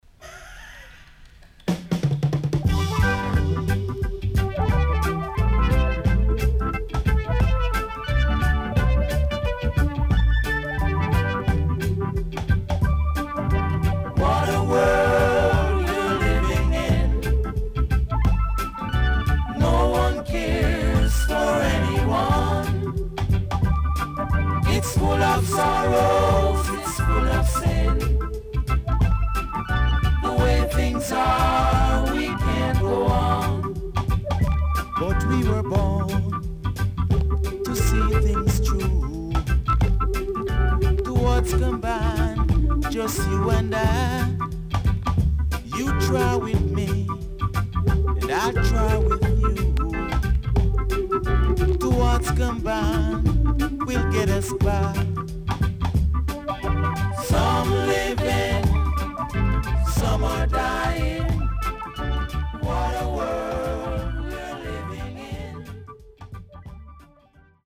HOME > REGGAE / ROOTS
Nice Vocal
SIDE A:少しチリノイズ入ります。